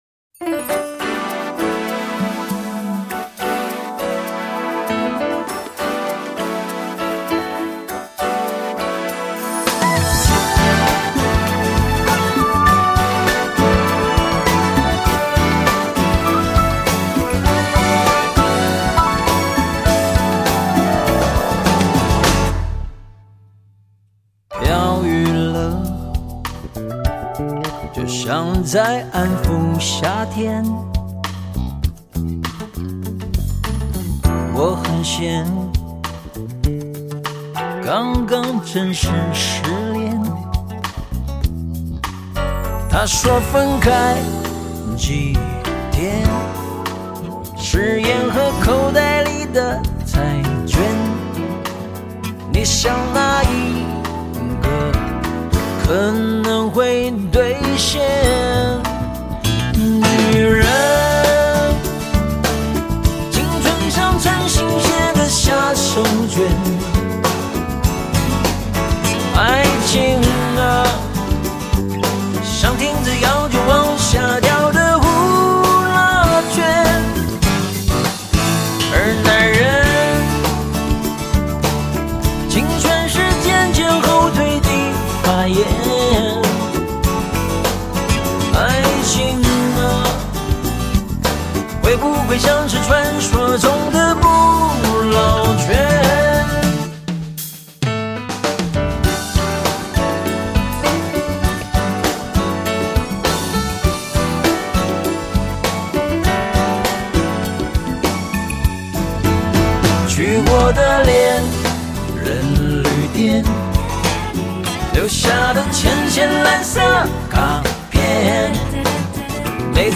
简单直白的歌词却深刻点击出青春与爱情之易逝，旋律如和风，词意如美酒，果然是首耐人寻味的压箱之作。